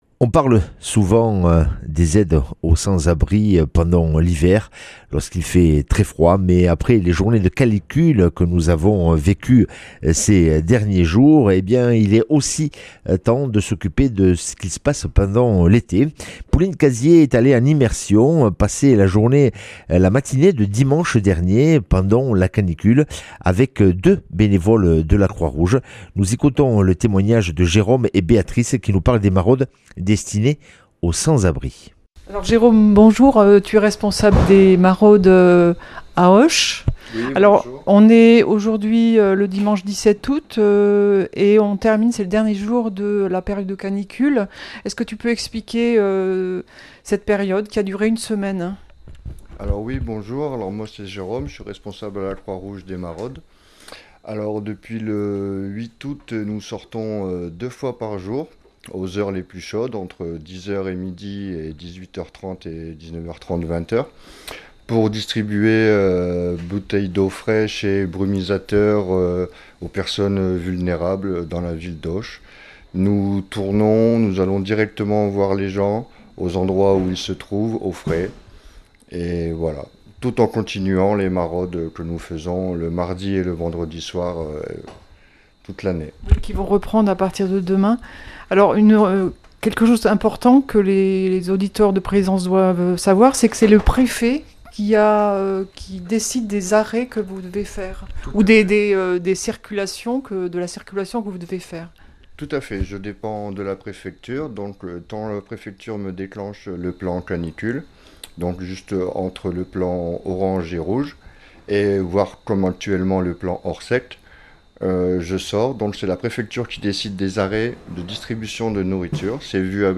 mercredi 20 août 2025 Interview et reportage Durée 10 min